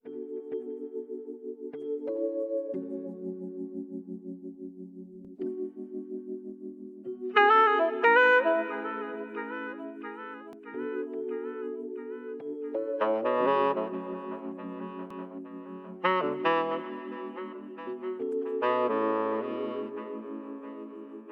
horns3